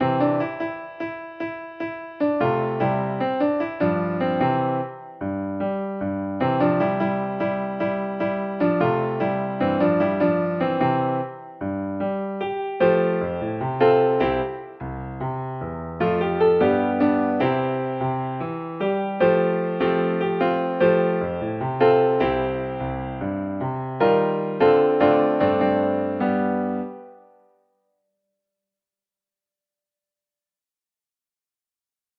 Tune Key: C Major